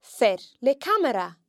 When R is not the first letter in a word and occurs next to a, o, or u, it is considered broad, and can be heard in fear (a man):